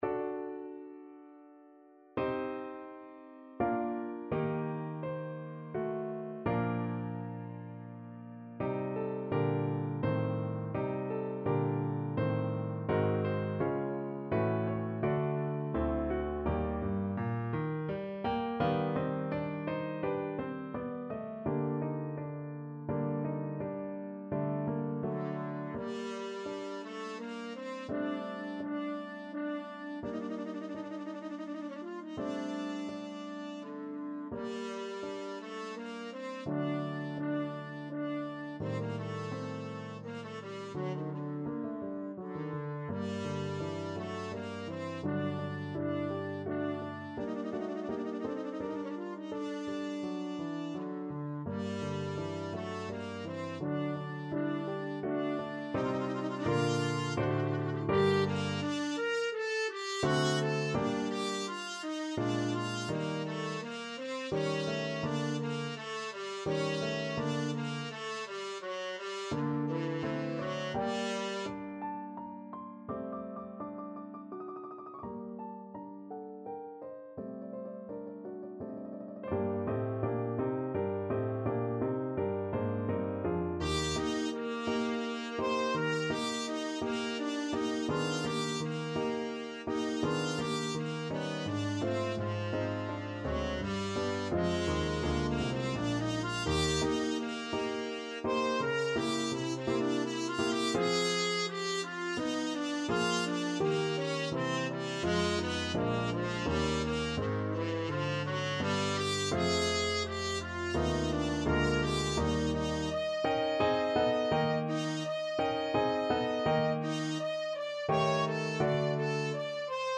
3/4 (View more 3/4 Music)
Andante =84
Classical (View more Classical French Horn Music)